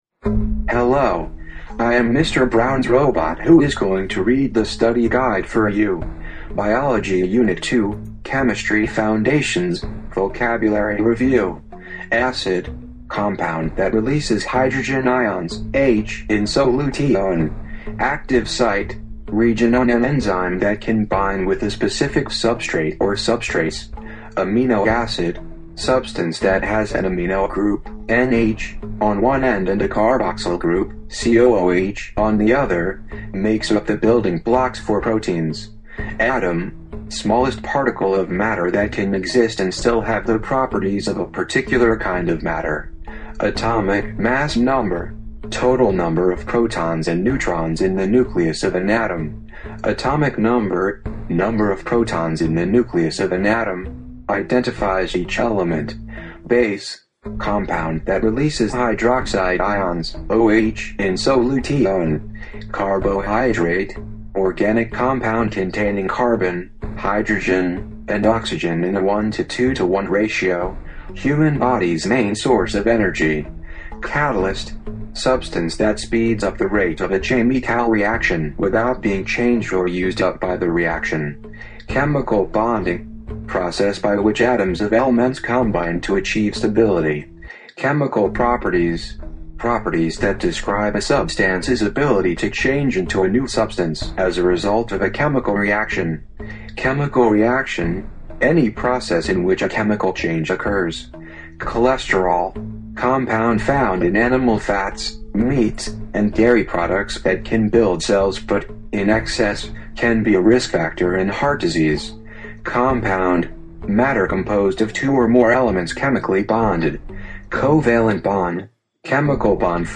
(This is an audio file which will read the above study guide to you for Unit 2 Chemistry Foundations.)